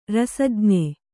♪ rasajñe